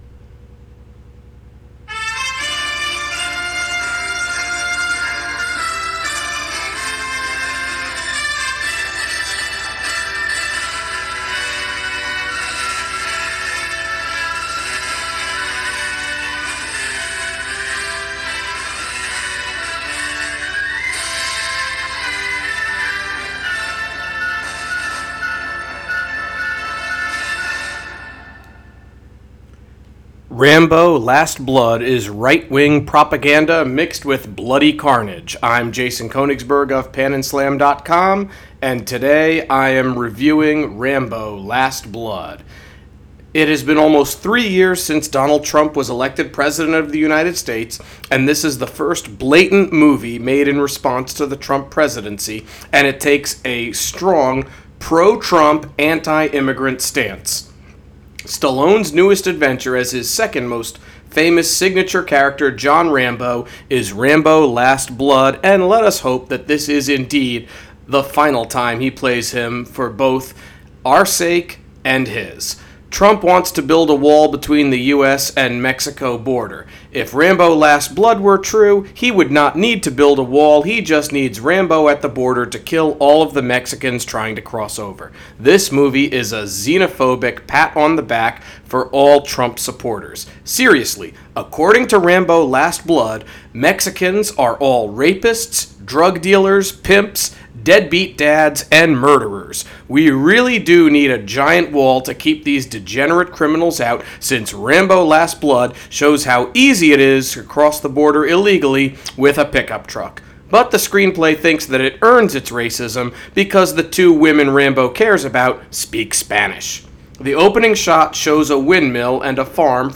Movie Review: Rambo: Last Blood